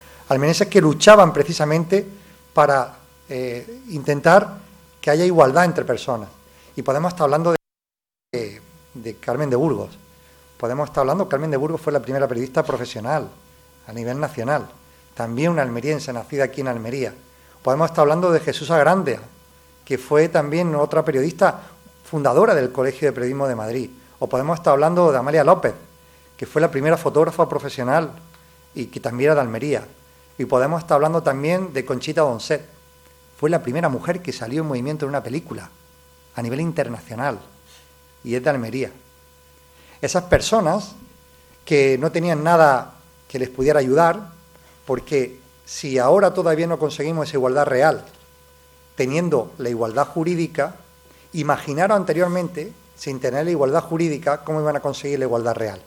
10-03_jornada_8m_diputac.__presidente_grandes_mujeres_almerienses.mp3